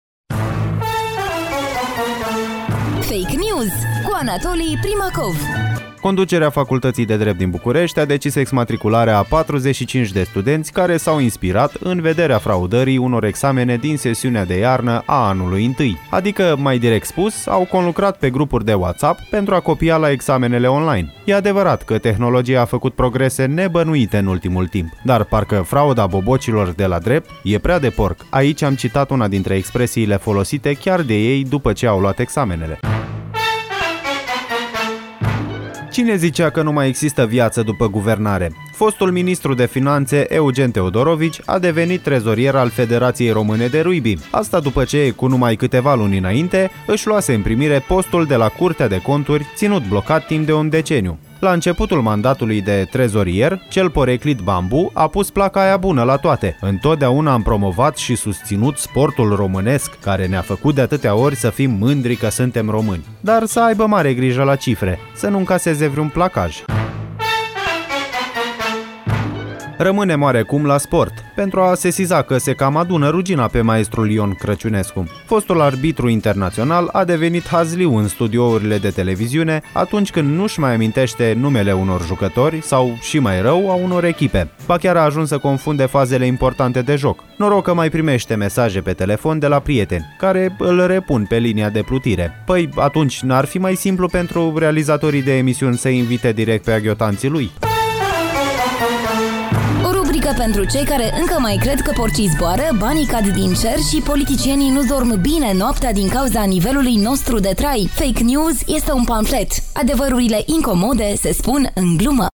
Genul programului: pamflet.